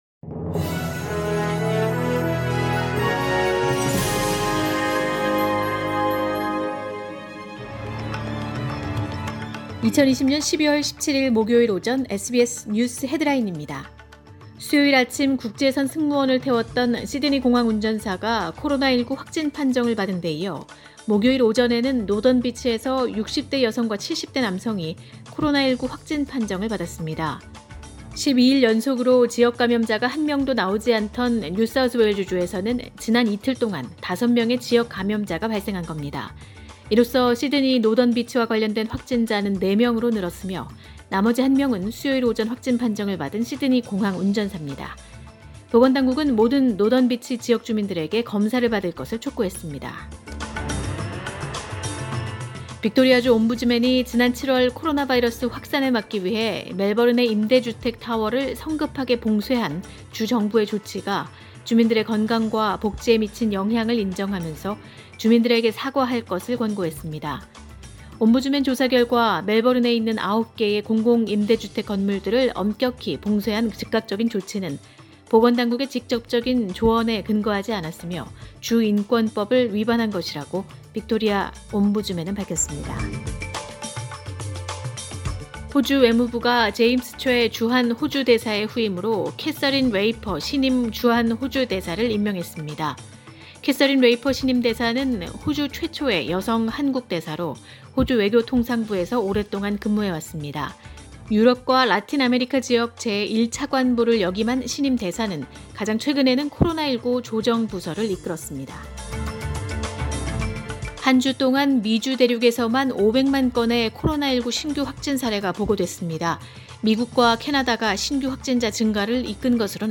2020년 12월 17일 목요일 오전의 SBS 뉴스 헤드라인입니다.